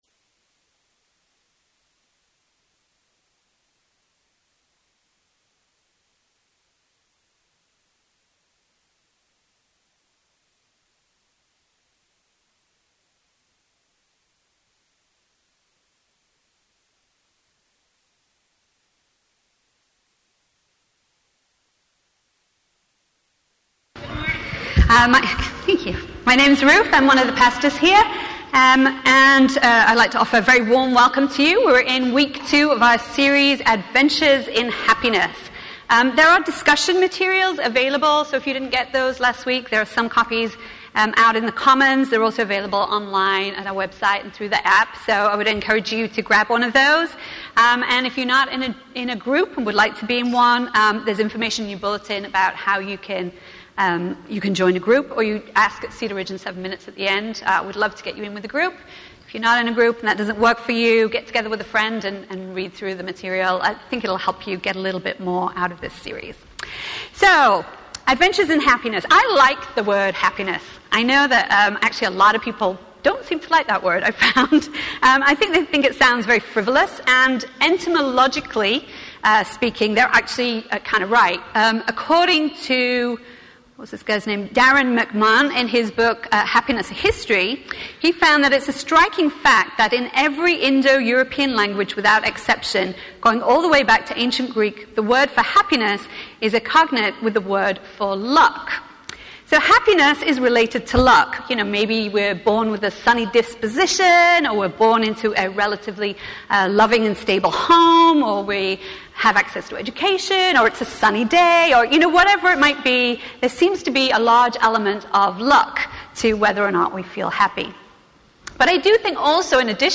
A message from the series "Reading Romans." This week, we wrapped up our series with a Thanksgiving-themed look at chapters 14-16.